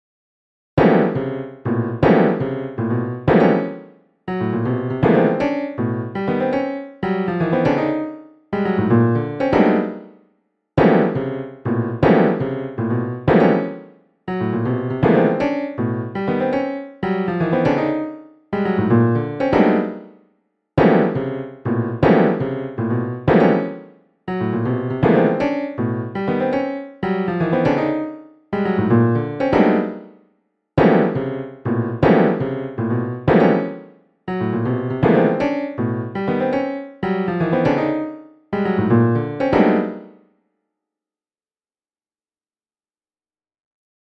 Il software ha tradotto in segnali audio
al pianoforte e al synth.